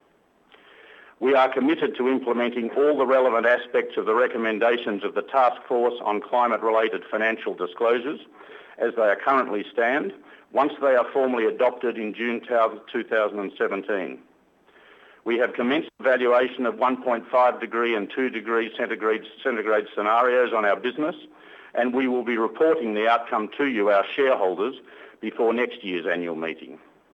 After months of pressure from shareholders, Oil Search confirmed at their AGM today that the company would commit to conduct and release an analysis of how it performs in a scenario where global warming is contained to 2 degrees and 1.5 degrees.